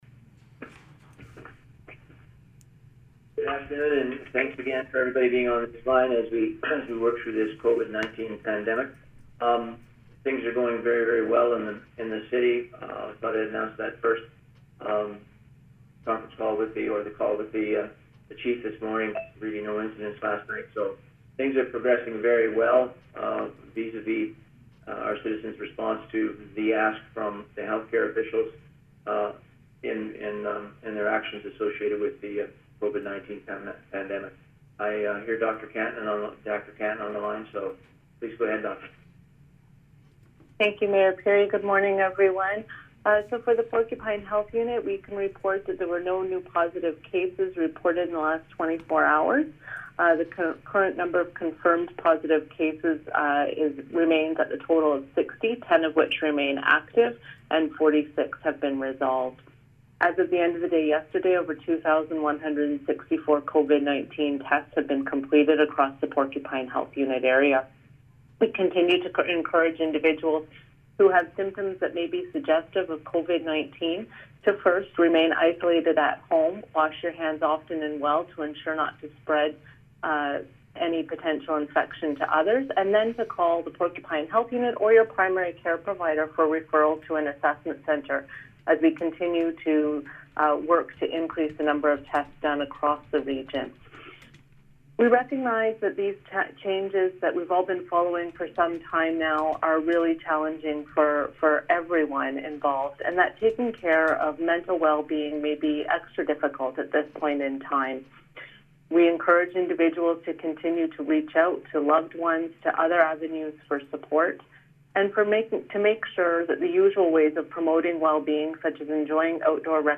Here is raw audio of today’s session: